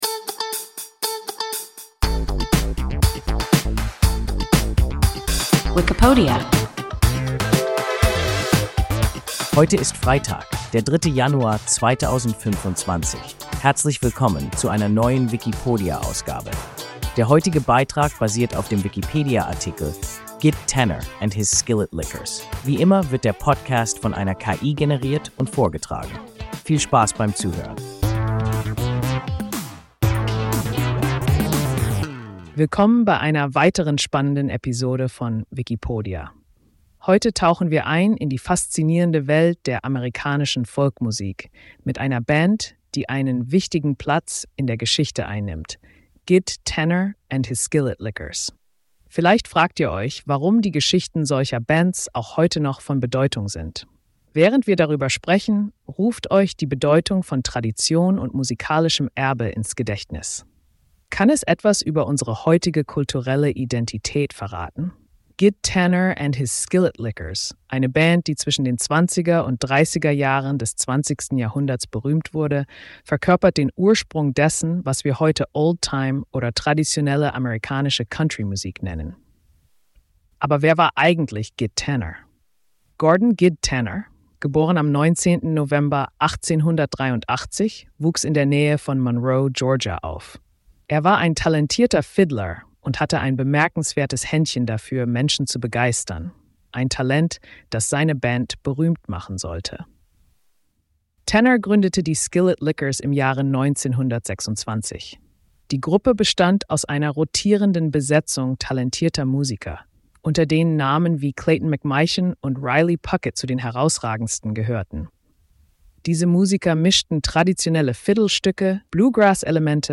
Gid Tanner and his Skillet Lickers – WIKIPODIA – ein KI Podcast